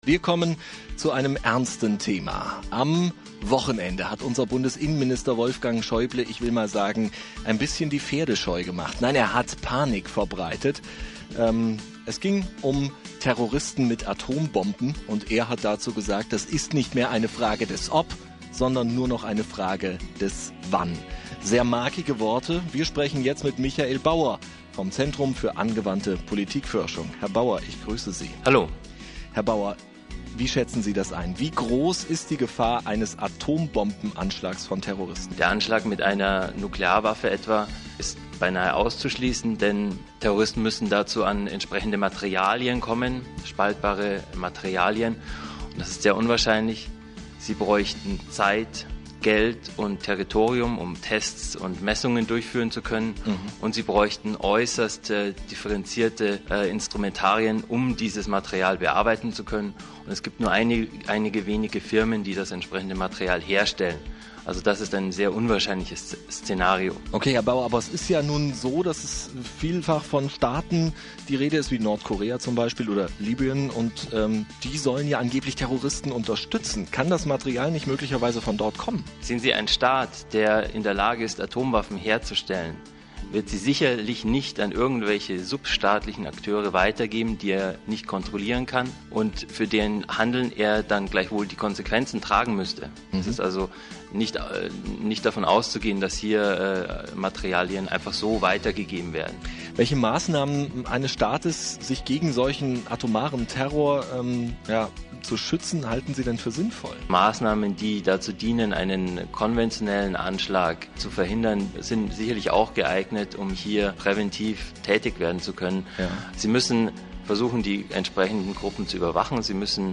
C·A·P Home > Aktuell > Interviews > 2007 > Atomterror im Westen?